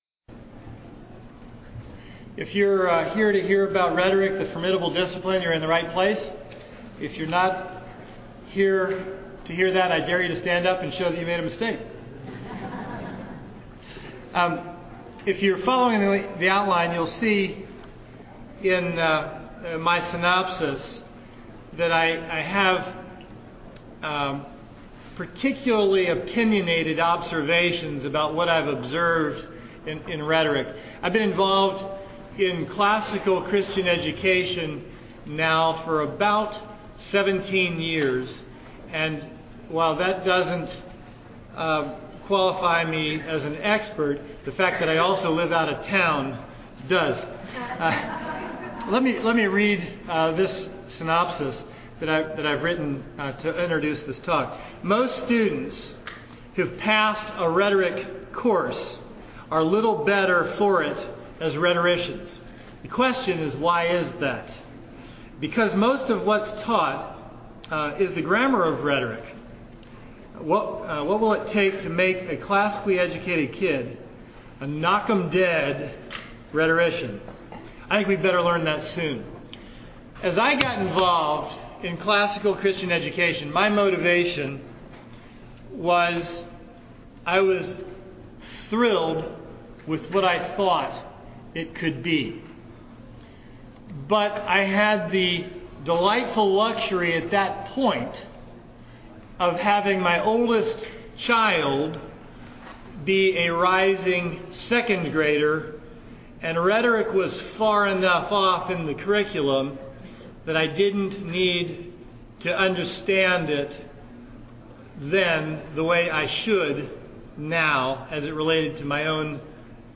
2009 Workshop Talk | 0:56:35 | 7-12, Virtue, Character, Discipline